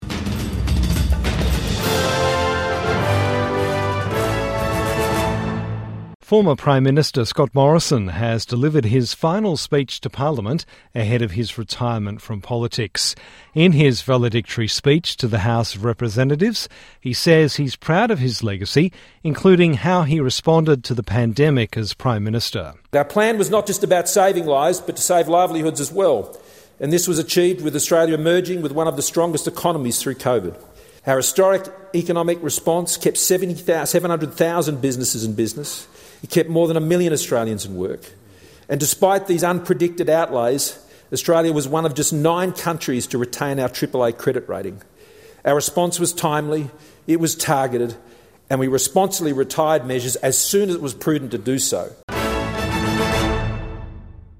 Scott Morrison delivers final speech to parliament